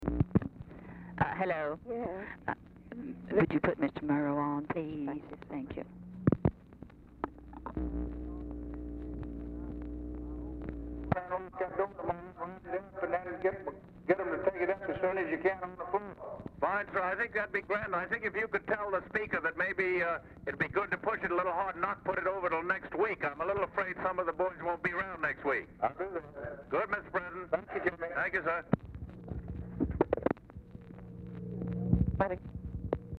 OFFICE SECRETARY ASKS TELEPHONE OPERATOR TO PUT EDWARD R. MURROW ON THE LINE; RECORDING OF CALL WITH JAMES ROOSEVELT STARTS AFTER CONVERSATION HAS BEGUN
Format Dictation belt
Specific Item Type Telephone conversation